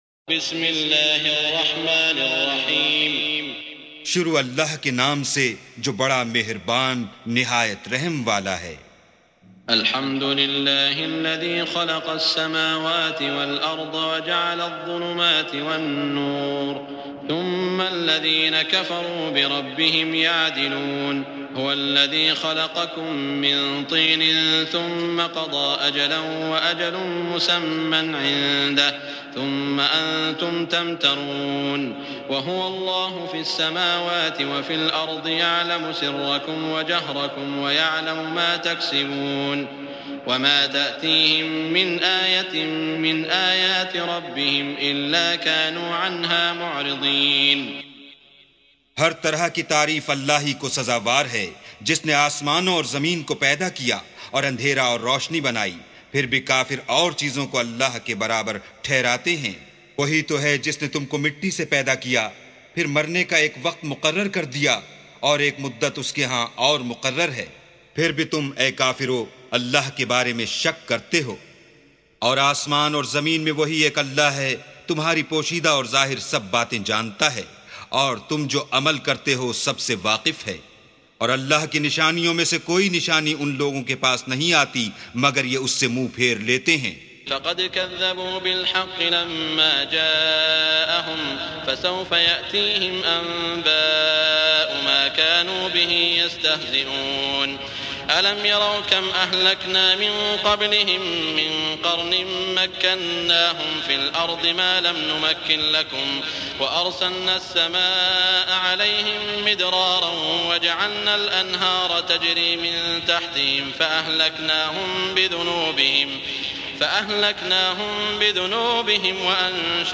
سُورَةُ الأَنۡعَامِ بصوت الشيخ السديس والشريم مترجم إلى الاردو